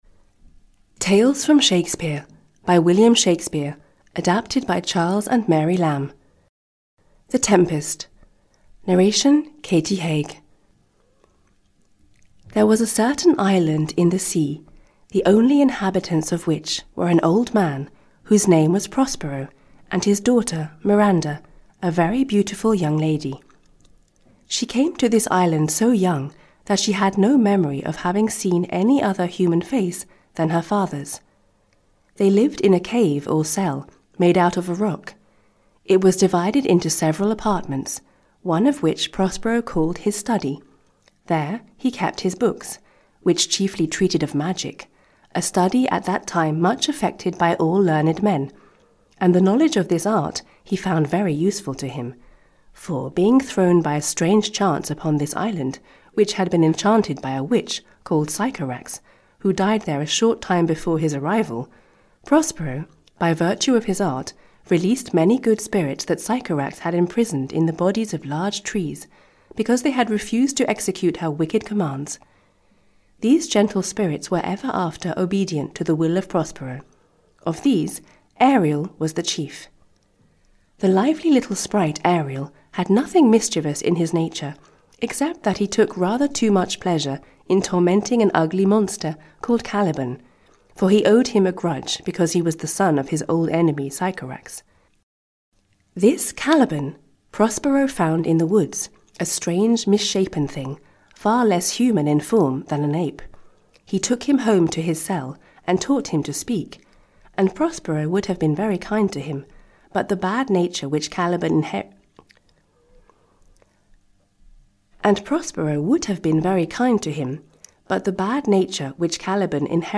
Audio knihaThe Tempest, a play by William Shakespeare – Summary (EN)
Ukázka z knihy